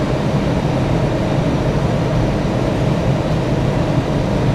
A320_cockpit_idle_l.wav